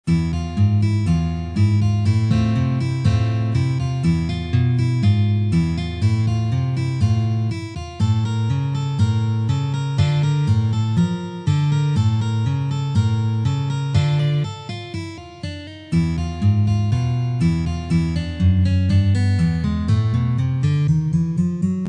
Tango Argentino
Arrangiamento molto semplice